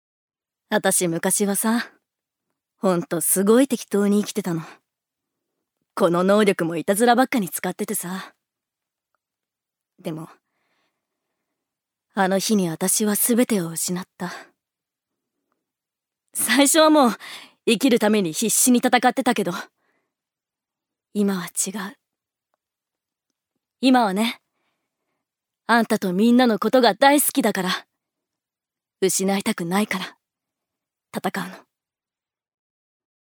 預かり：女性
セリフ１